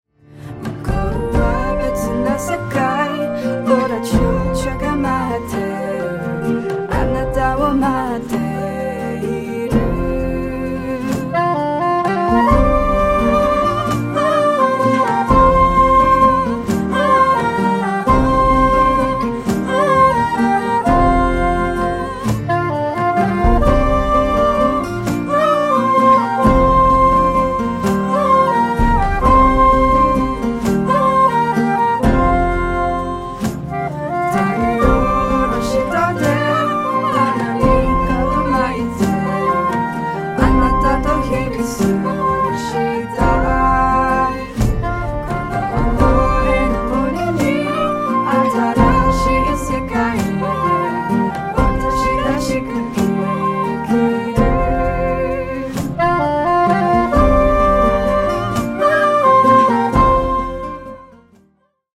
Avant le studio...